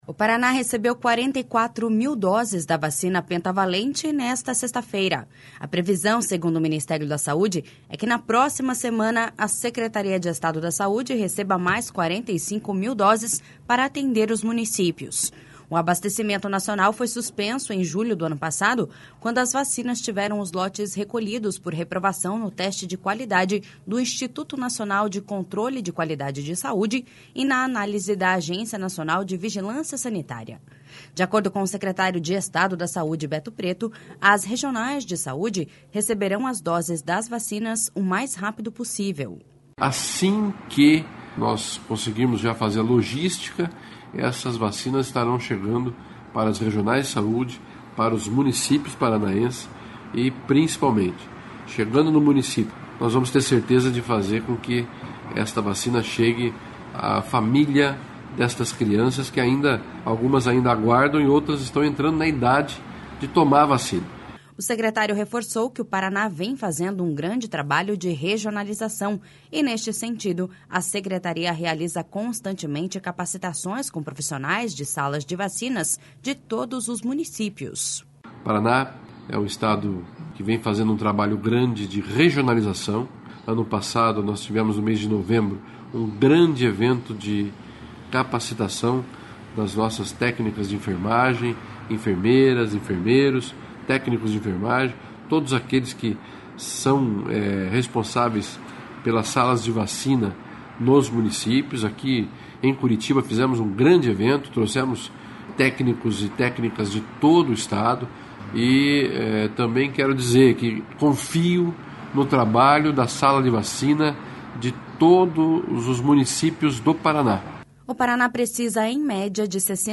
De acordo com o secretário de Estado da Saúde, Beto Preto, as regionais de saúde receberão as doses das vacinas o mais rápido possível.// SONORA BETO PRETO.//